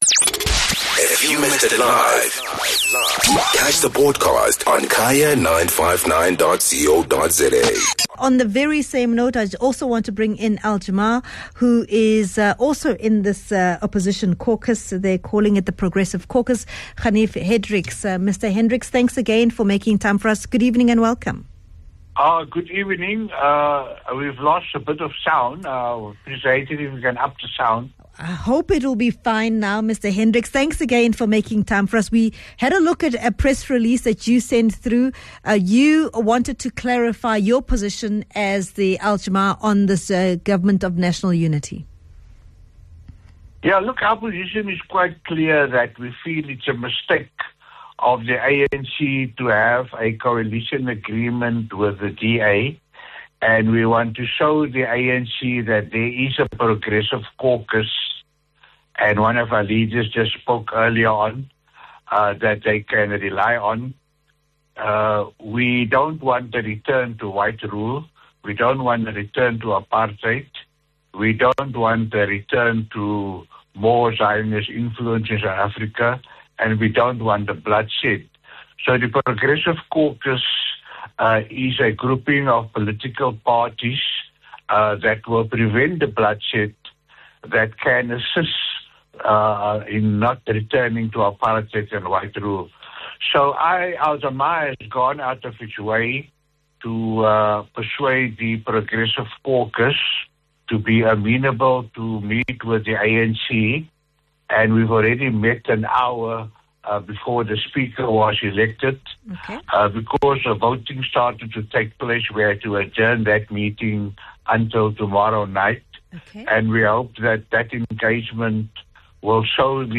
Guest: Ganief Hendricks - Al Jama-ah Party Leader